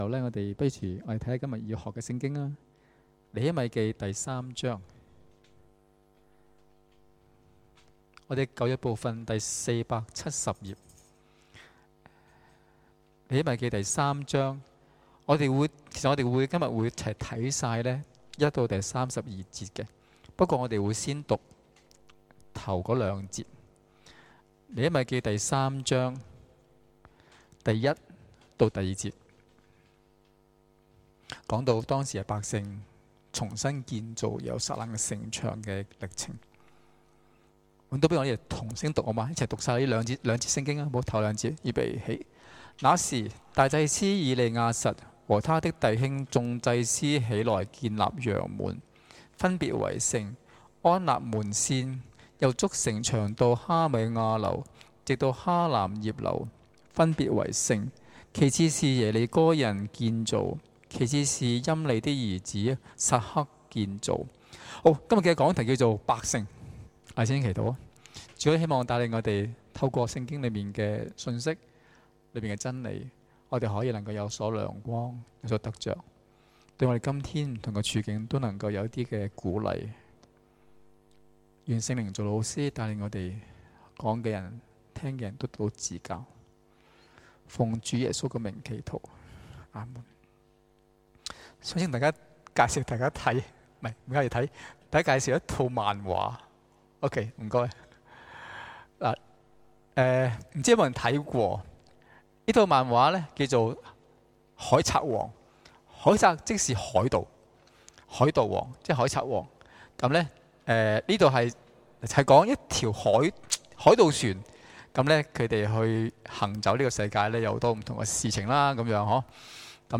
2017年2月4日及5日崇拜講道